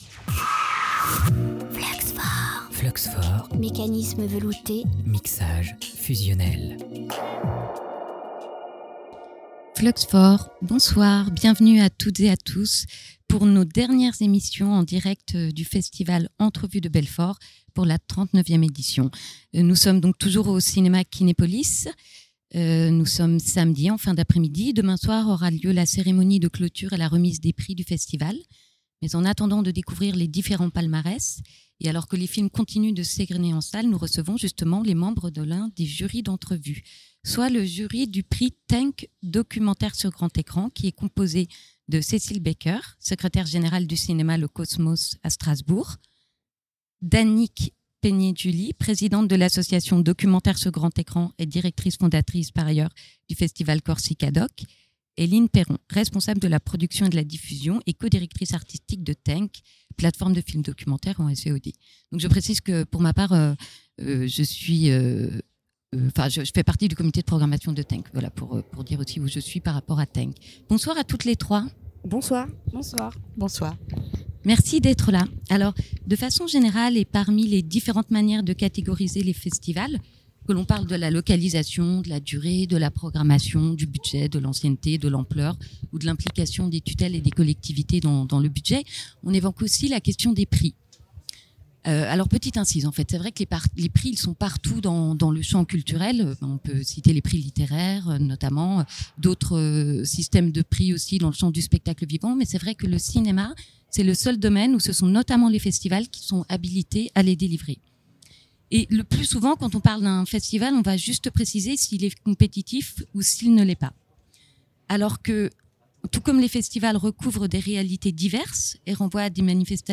Toutes trois sont les membres du jury du prix Tënk-Documentaire sur grand écran.